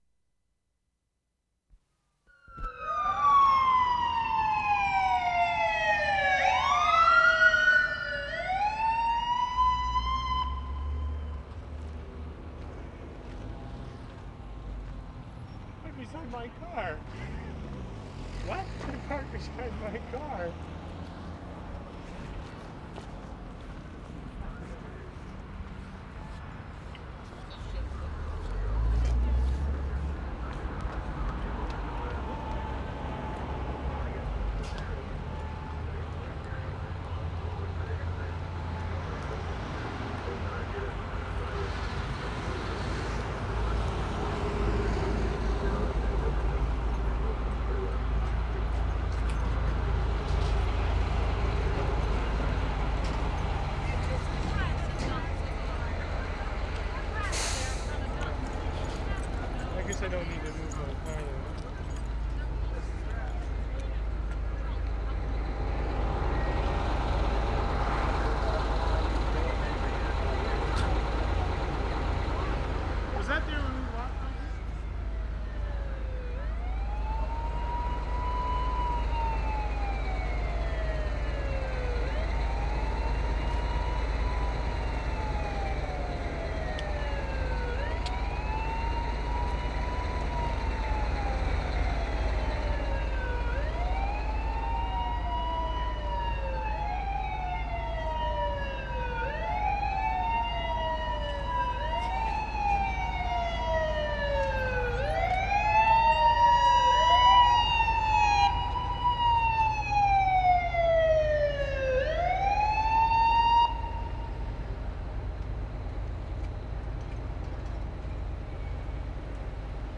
SIRENS, foot of Main Street 2'55"
1. Opens with fire siren flashing past, stops suddenly.
1'25" more sirens, distant and approaching.
1'50" sudden cut-off of siren.